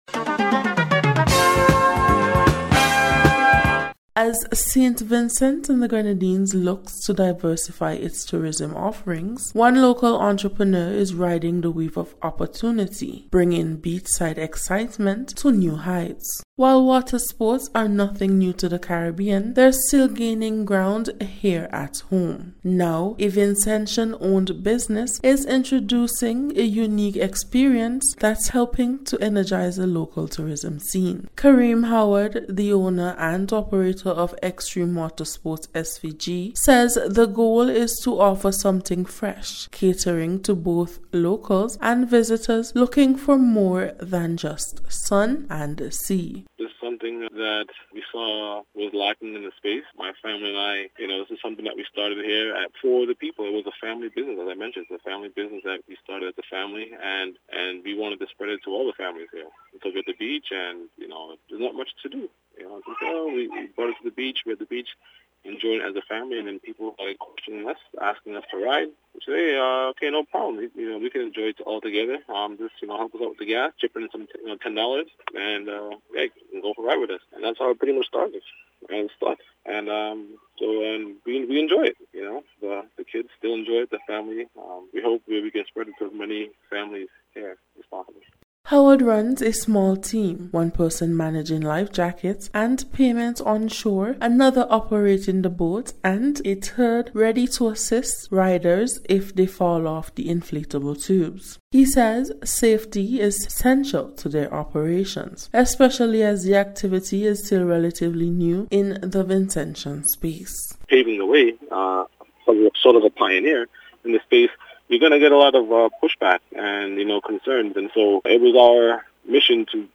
NBC’s Special Report- Monday 4th July,2025